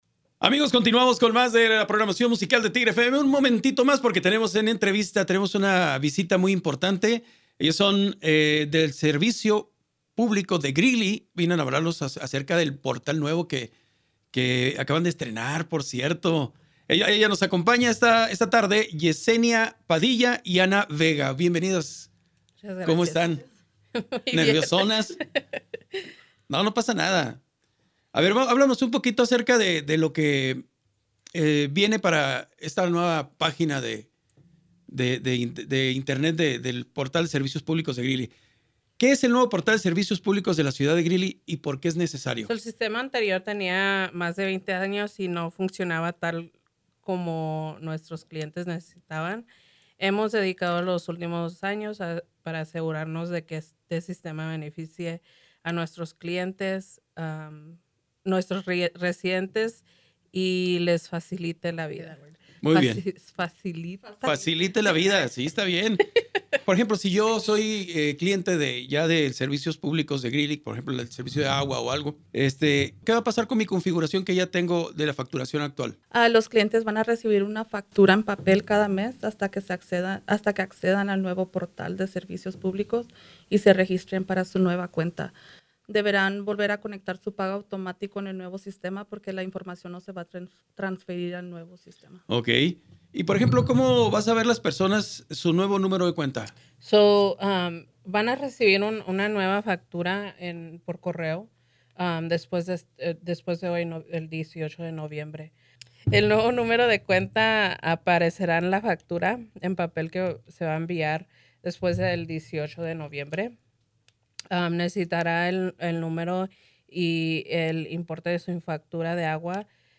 Entrevista de radio KGRE Cómo vincular varias cuentas ¡Greeley da la bienvenida a una nueva era en la gestión de servicios pú